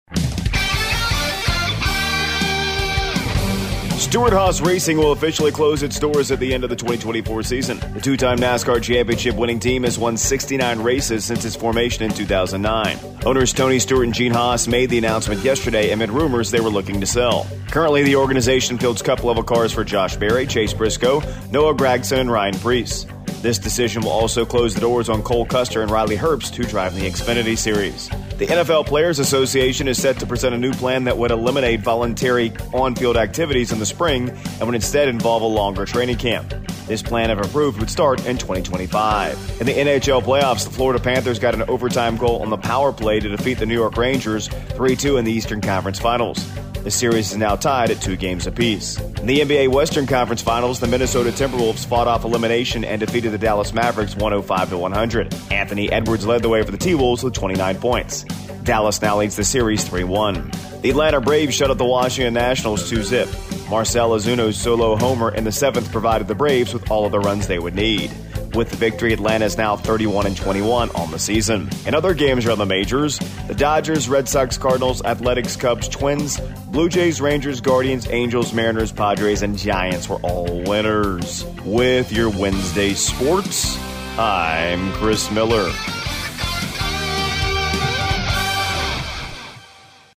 AUDIO: Tuesday Morning Sports Report